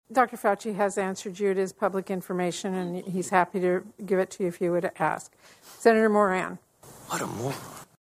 That led to a hot-mic moment from Fauci as Kansas US Senator Jerry Moran was set to ask questions.